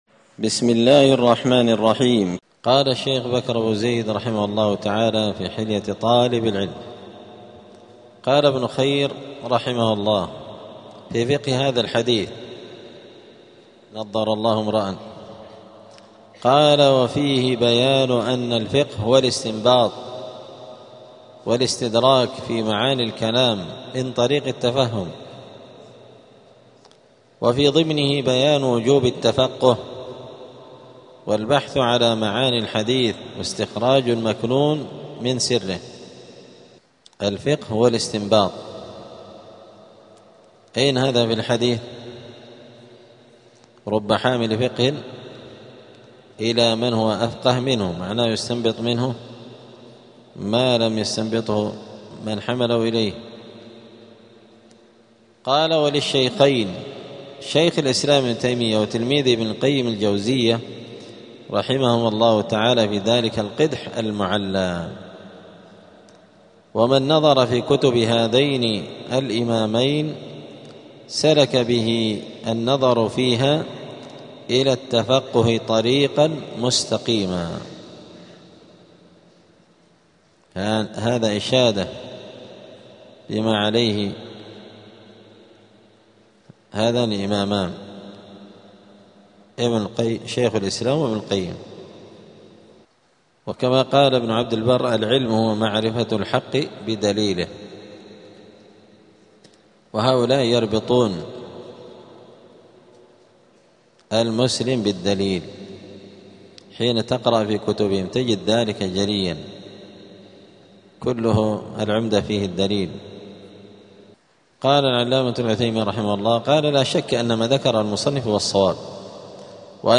*الدرس الرابع والستون (64) فصل آداب الطالب في حياته العلمية {التفقه بتخريج الفروع على الأصول}.*
دار الحديث السلفية بمسجد الفرقان قشن المهرة اليمن